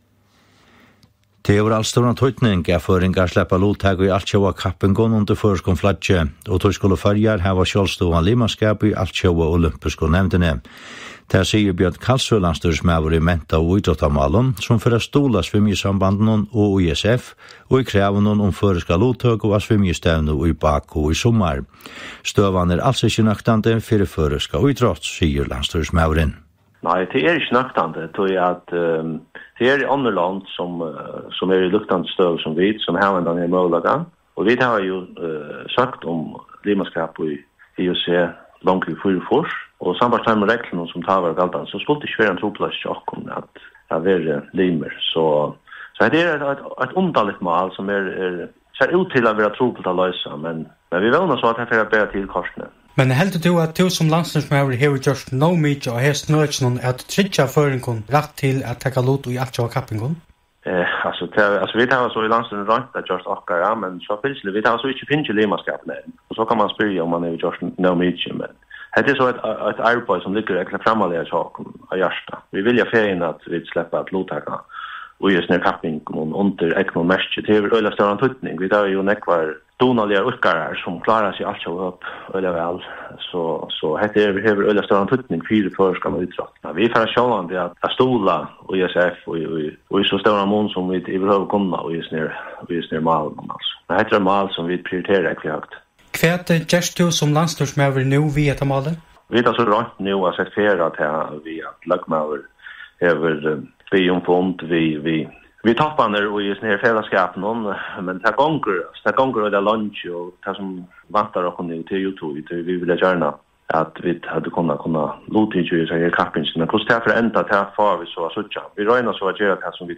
Brot úr útvarpstíðindunum hjá Kringvarpi Føroya, týsdagin hin 3. mars 2015 kl 12:30, um støðuna nú Føroyar ikki sleppa at luttaka á Evropeisku JuniorMeistarastevnuni í svimjing 2015.